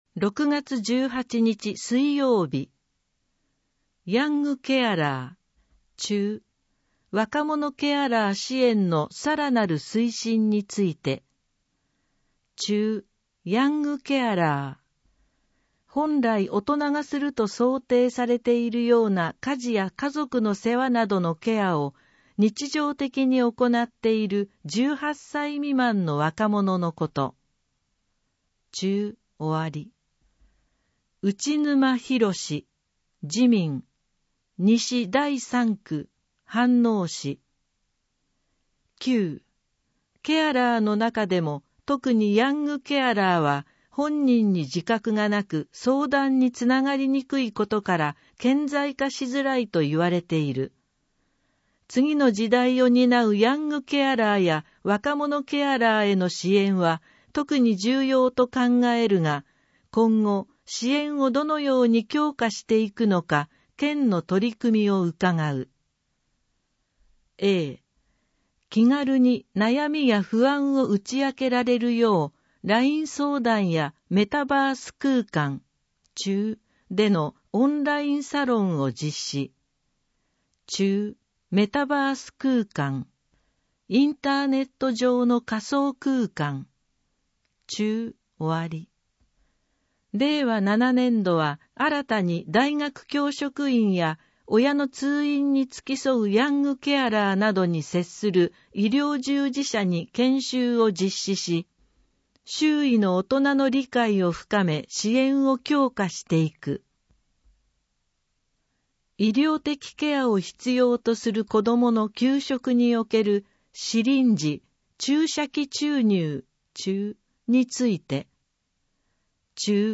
「Windows Media Player」が立ち上がり、埼玉県議会だより 182号の内容を音声（デイジー版）でご案内します。